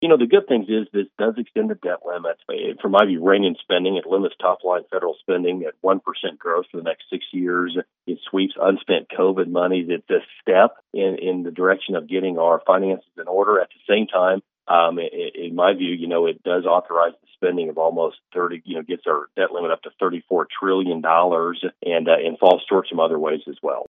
Kansas 1st District Congressman Tracey Mann joined KMAN’s Morning News Tuesday, and says the deal is a mixed bag.